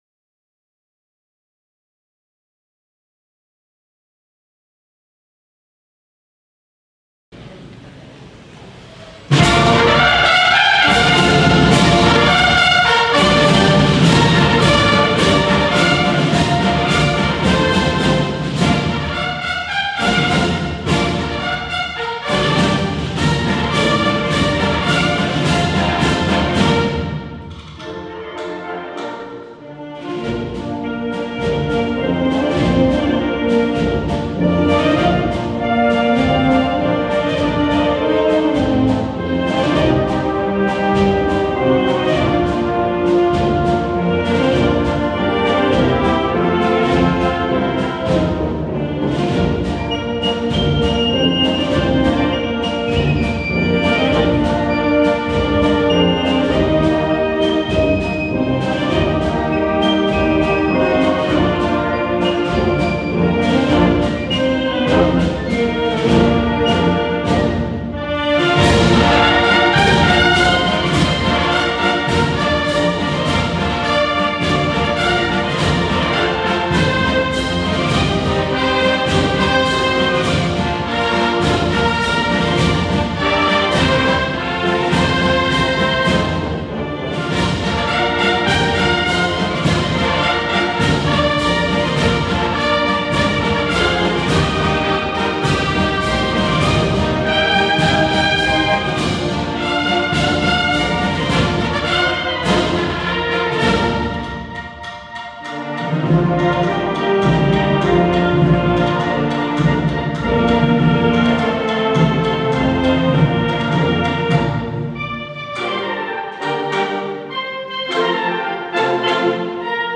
XXXIII acto de Exaltación a Nuestra Señora de la Encarnación